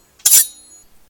Other Sound Effects
sword.2.ogg